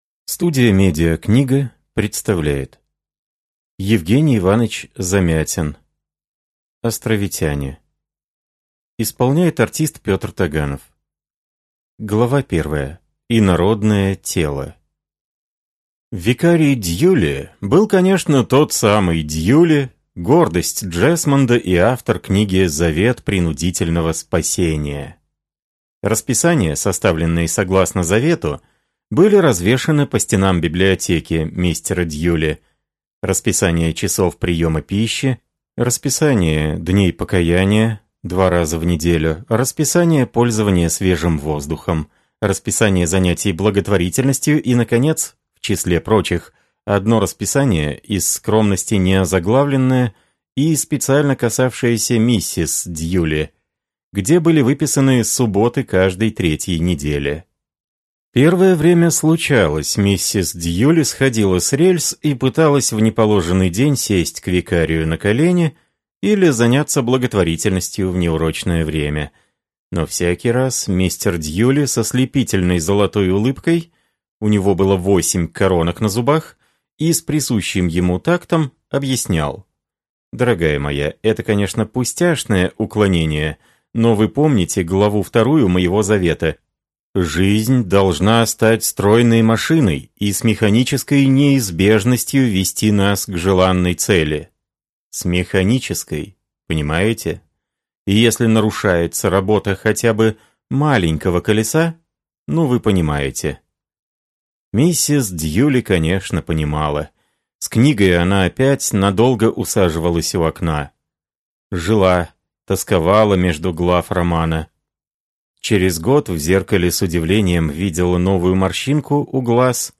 Аудиокнига Островитяне | Библиотека аудиокниг